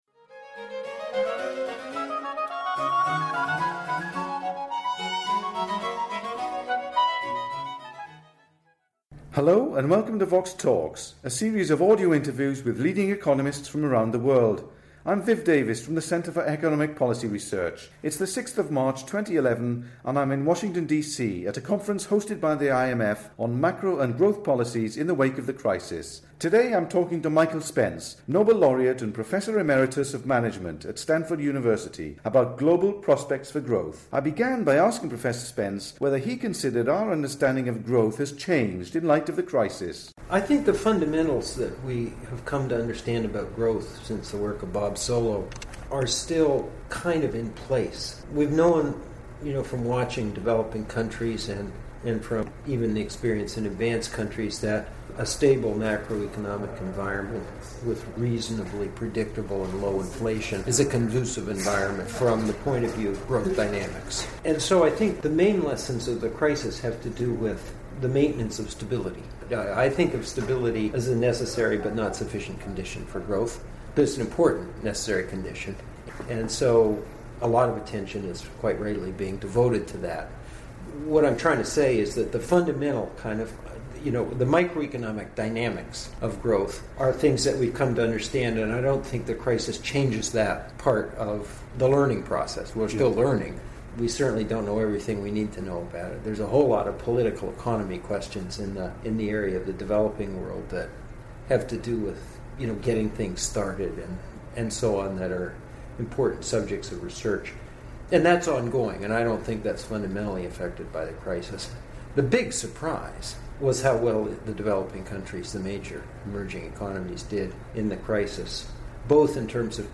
He describes the current divergence between growth and employment in the US economy. They also discuss global imbalances, fiscal coordination in Europe, the global investment rate and the threat of rising oil prices to global growth. The interview was recorded in Washington DC in March 2011 at the IMF conference, ‘Macro and Growth Policies in the Wake of the Crisis’.